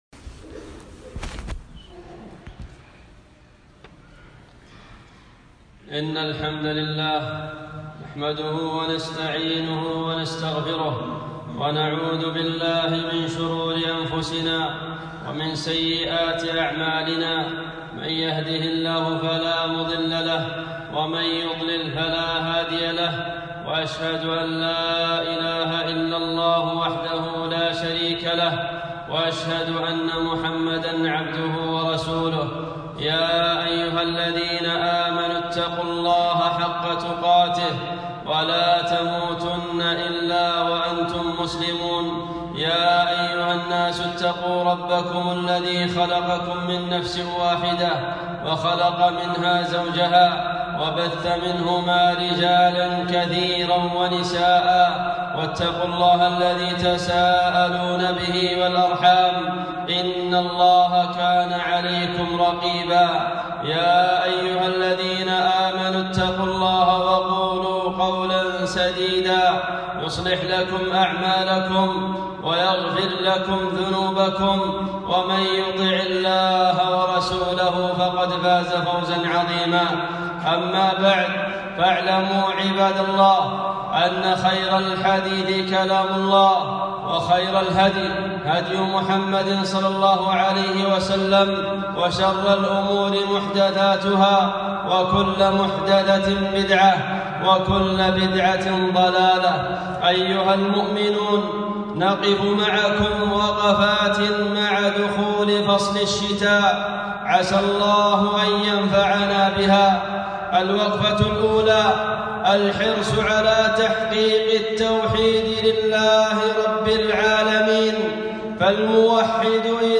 خطبة - وقفات مع فصل الشتاء